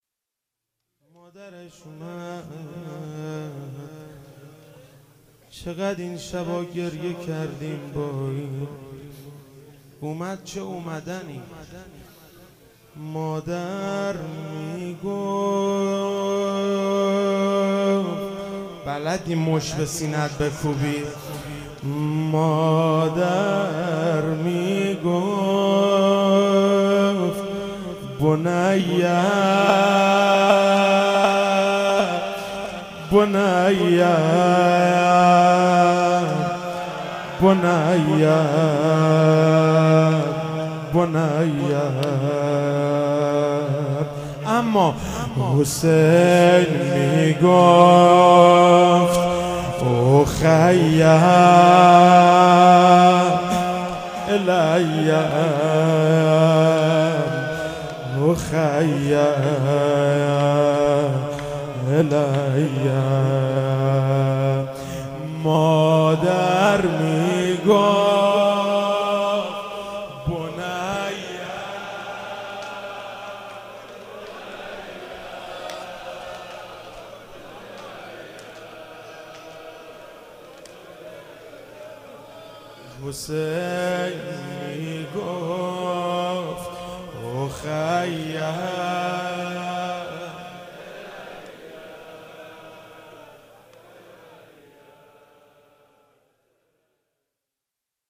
فاطمیه 95_شب ششم_روضه_مادر میگفت بنی